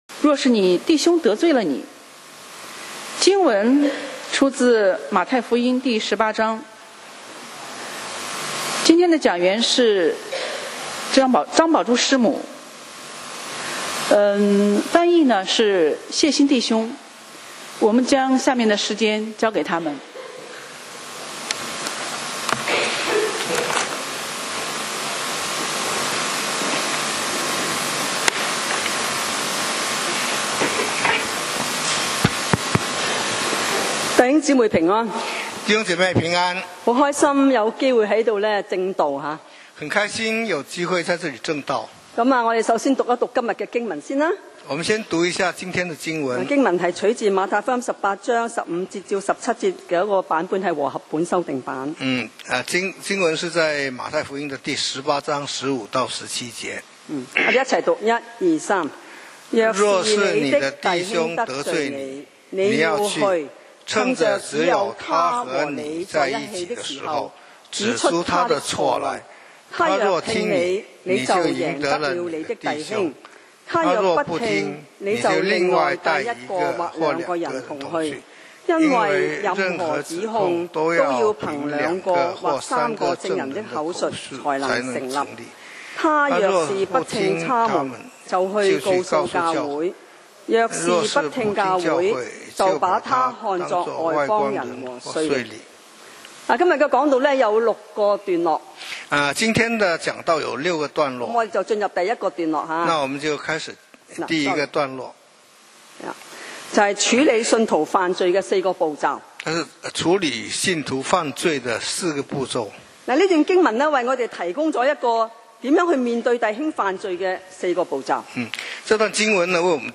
講道 Sermon 題目 Topic：若是你的弟兄得罪你 經文 Verses：太18:15-17. 15倘若你的弟兄得罪你，你就去趁着只有他和你在一处的时候，指出他的错来。